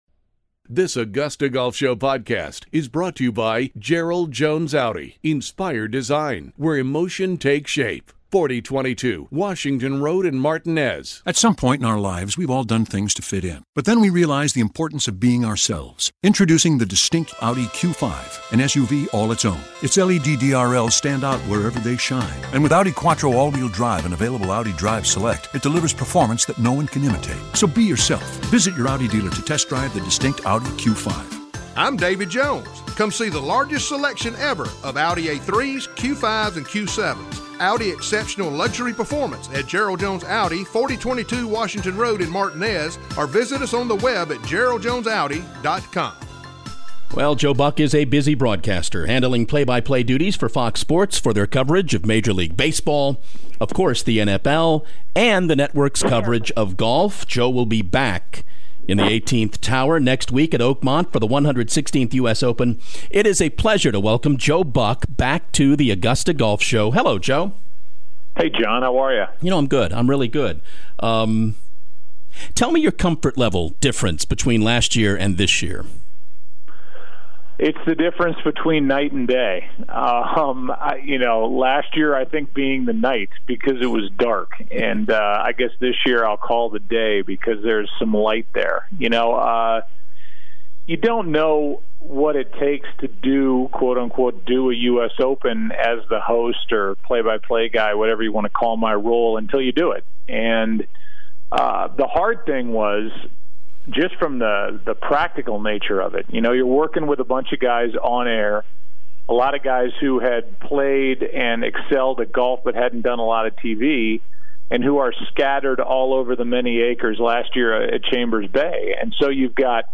Joe Buck: The Augusta Golf Show Interview